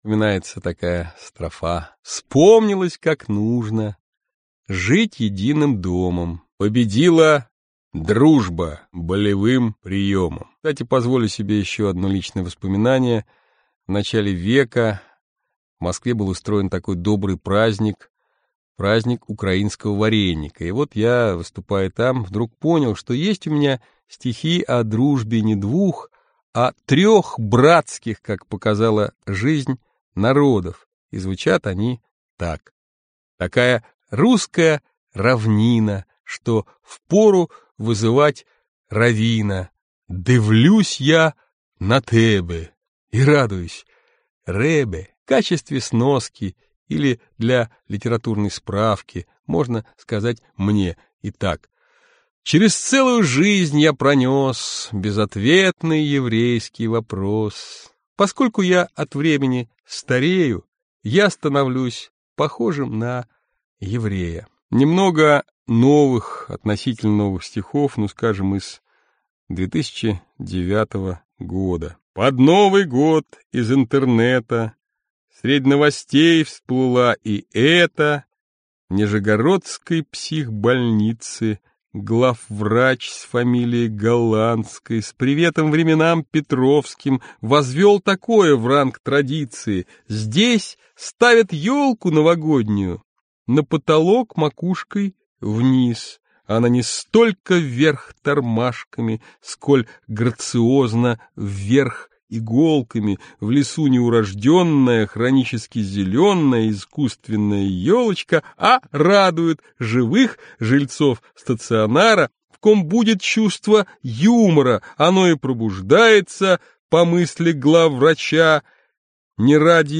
Аудиокнига Тот самый, но другой | Библиотека аудиокниг